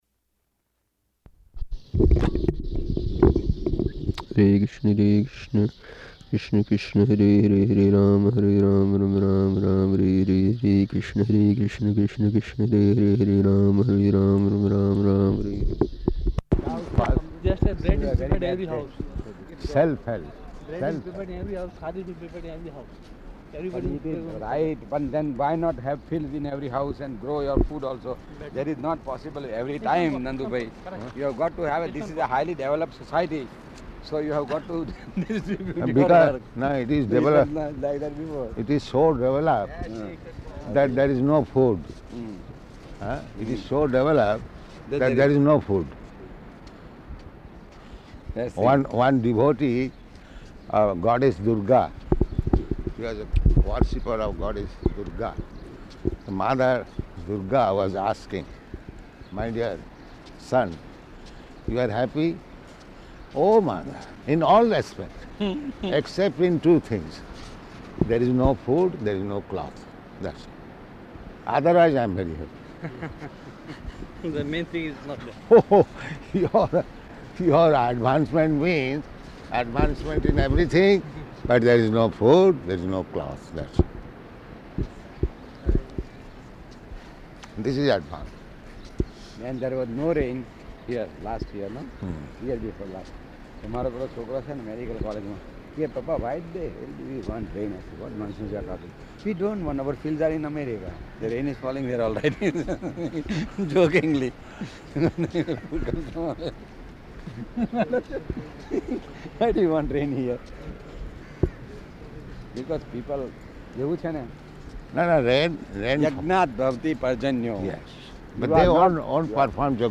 Type: Walk
Location: Bombay